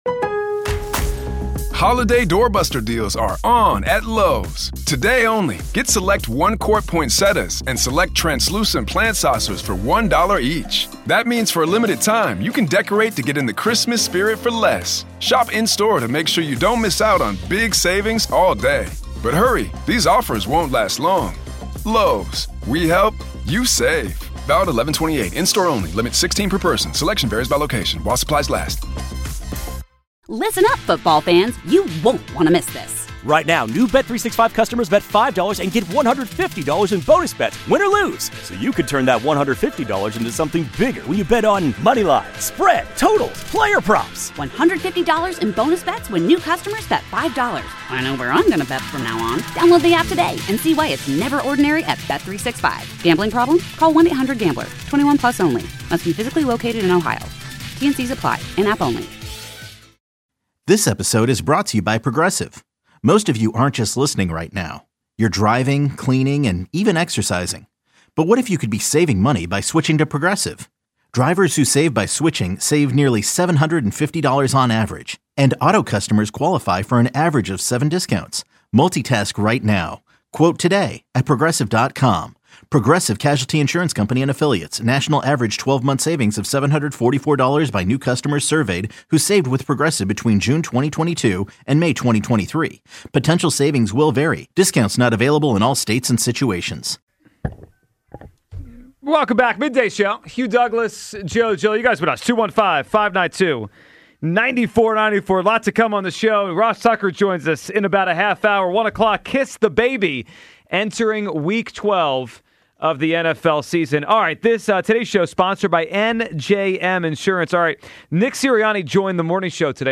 Another week, another replay of Nick Sirianni appearing on the morning show. Here everything Coach Sirianni had to say about the Lions game, upcoming Cowboys game, and offensive drama.